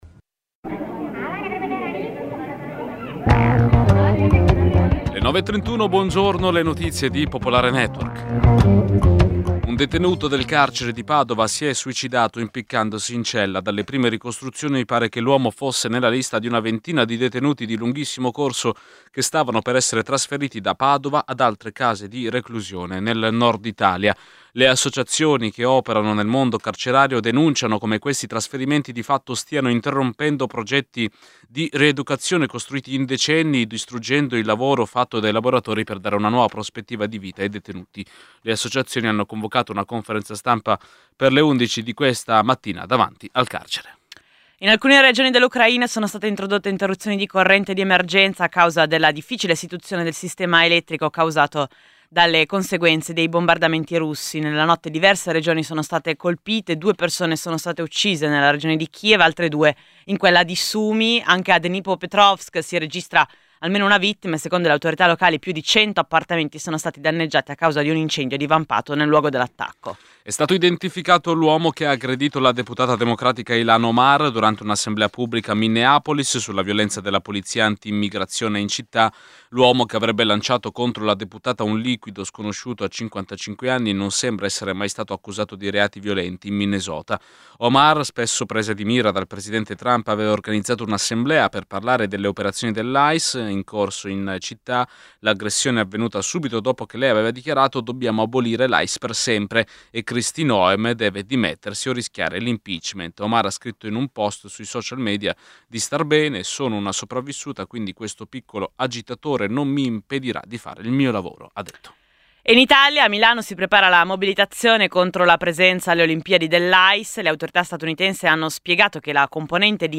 Giornale radio
Annunciati dalla “storica” sigla, i nostri conduttori vi racconteranno tutto quello che fa notizia, insieme alla redazione, ai corrispondenti, agli ospiti.